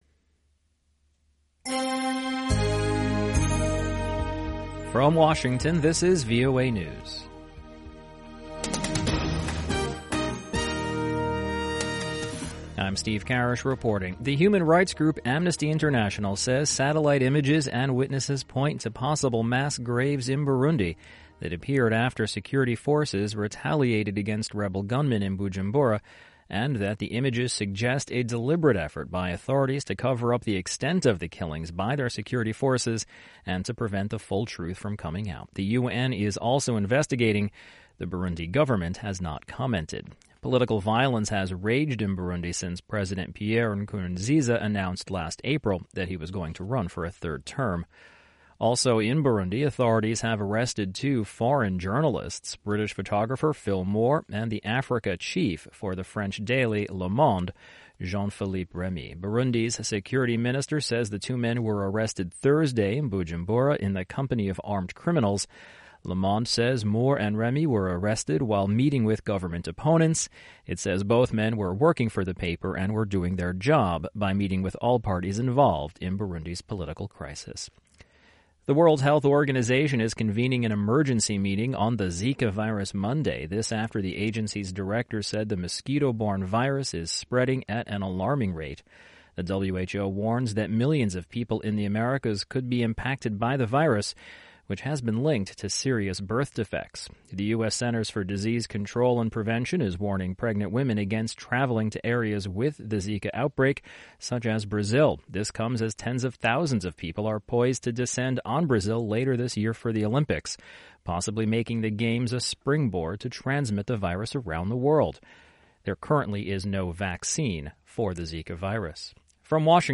VOA English Newscast: 1400 UTC January 29, 2016